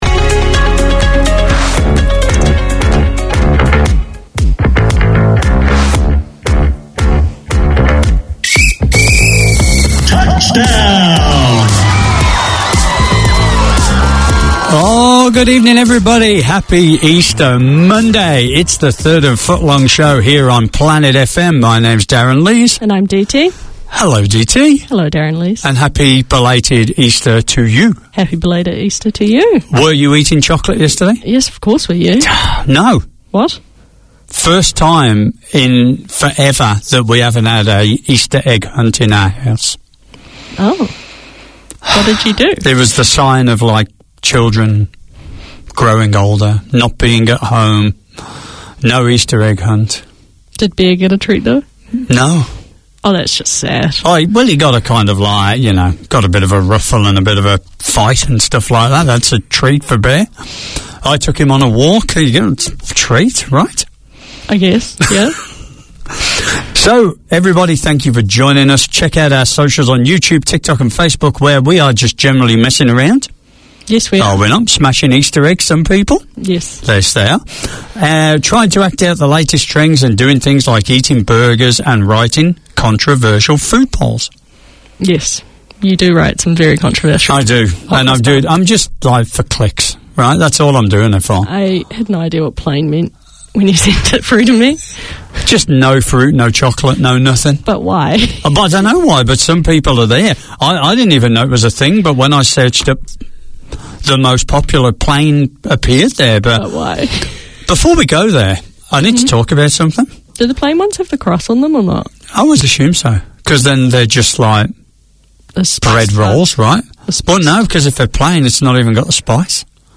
In this one-off special, Planet FM presents a snapshot of Te Matatini 2023, with vox-pops and interviews undertaken in the marketplace at Ana Wai / Eden Park where the festival took place. A celebration of the best of Kapa Haka across Aotearoa New Zealand, this year's Te Matatini festival was hosted by Ngāti Whātua Ōrākei.